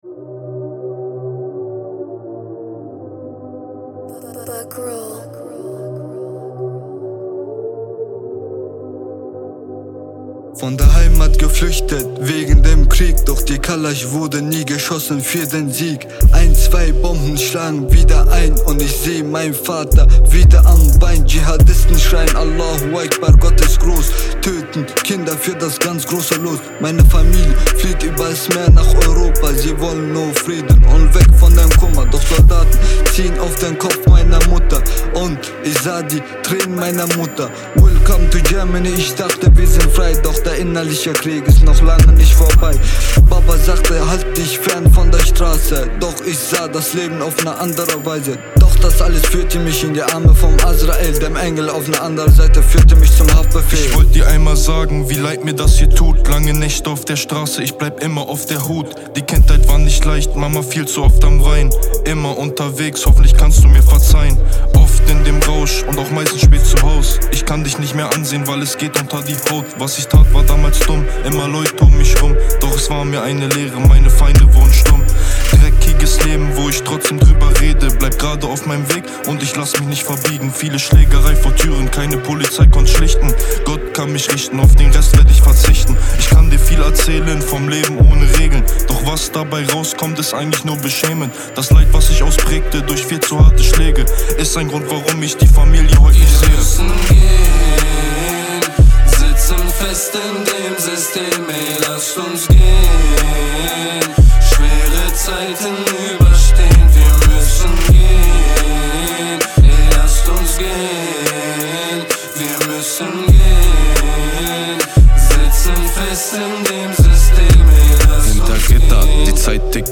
- 4-Tage RAP-Projekt in der Abteilung Göttingen
Sieben junge Inhaftierte der Jugendanstalt Hameln